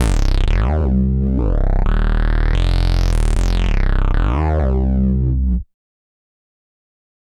synth02.wav